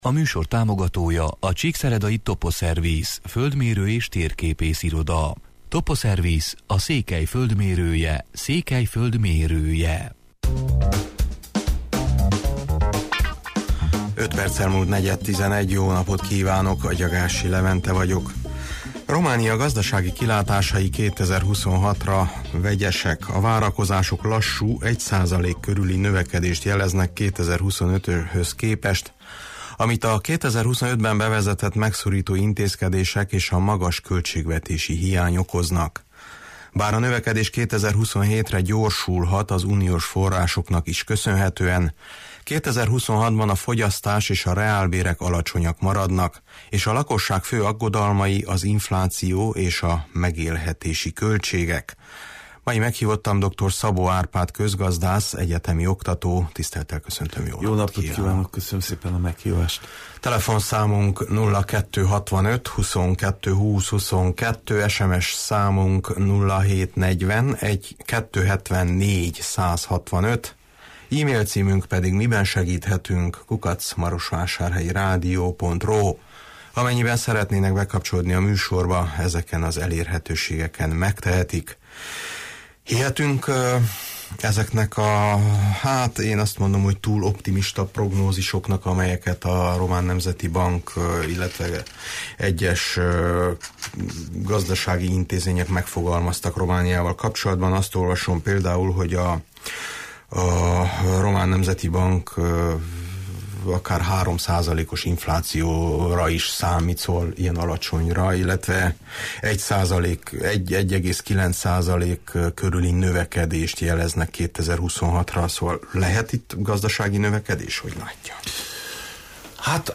közgazdász, egyetemi oktató